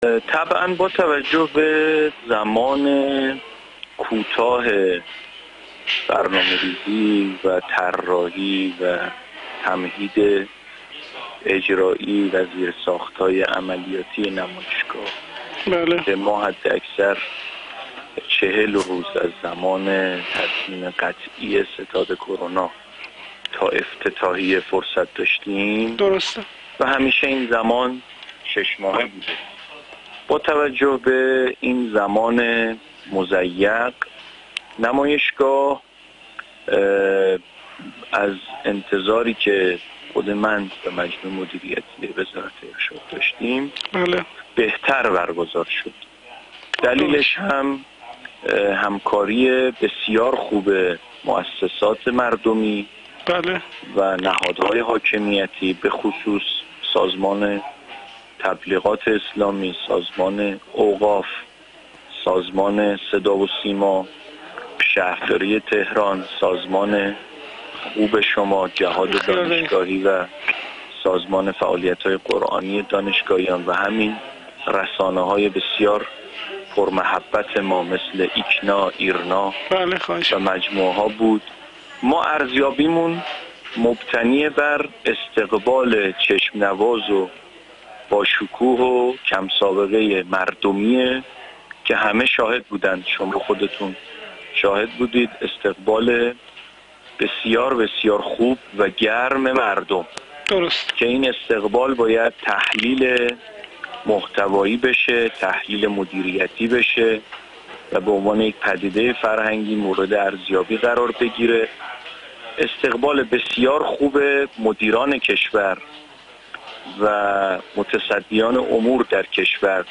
علیرضا معافعلیرضا معاف، رئیس بیست و نهمین نمایشگاه بین‌المللی قرآن کریم در گفت‌وگو با خبرنگار ایکنا در مورد برگزاری این رویداد و برآورده شدن انتظارات از آن گفت: طبعاً با توجه به زمان کوتاه برای برنامه‌ریزی، طراحی، تمهیدات اجرایی و زیرساخت‌های عملیاتی نمایشگاه، حداکثر 40 روز از زمان تصمیم قطعی ستاد کرونا تا افتتاحیه فرصت داشتیم این در حالی است که این زمان پیش از این شش ماه بوده است.